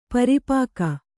♪ pari pāka